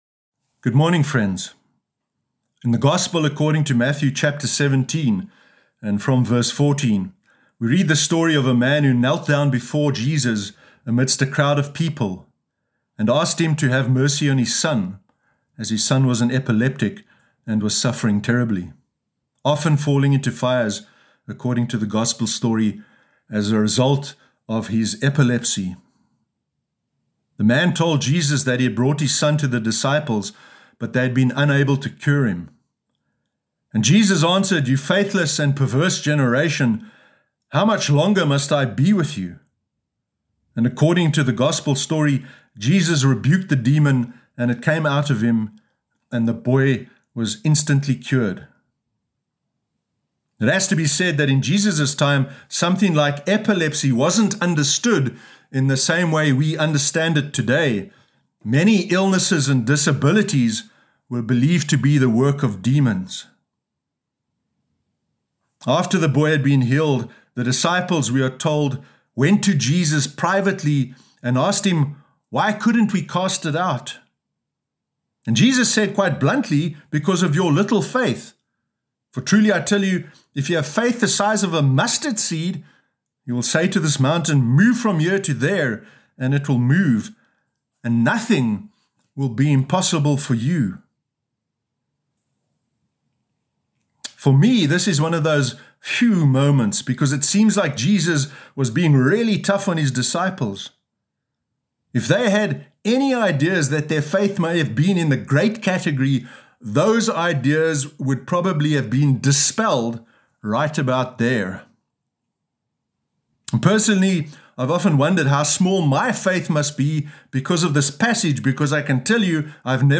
Sermon Sunday 23 August 2020